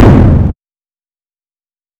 shoot.wav